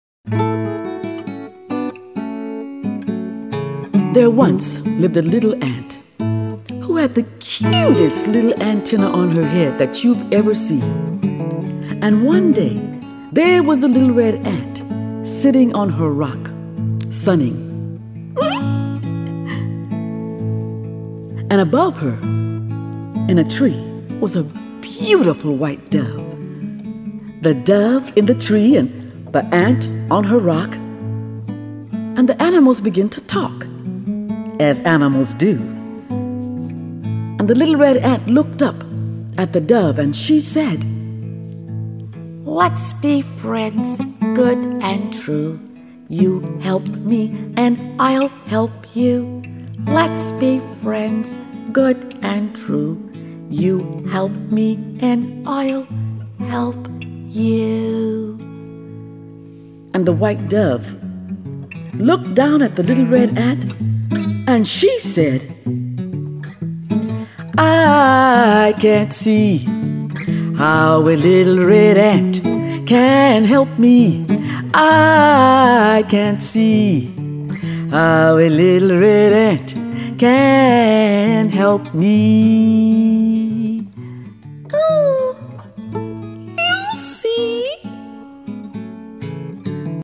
on vocals, banjo, guitar, and bass
. . . . .  All in all, this is one of the most entertaining and best produced storytelling